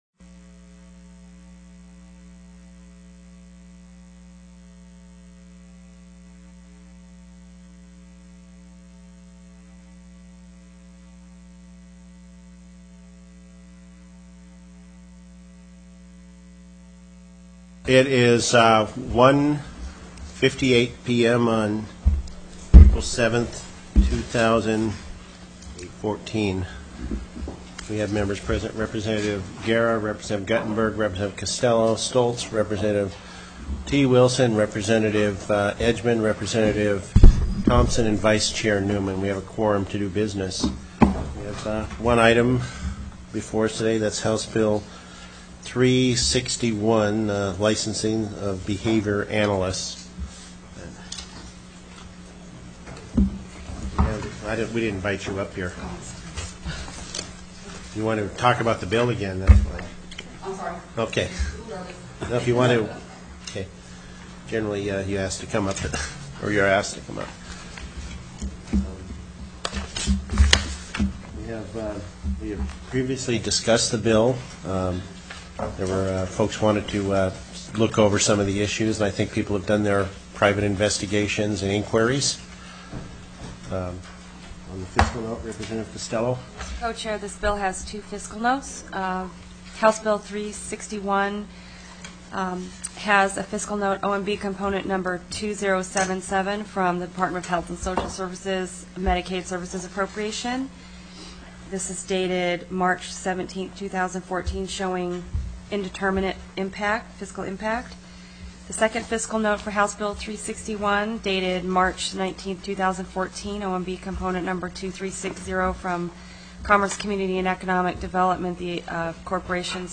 + teleconferenced
HOUSE FINANCE COMMITTEE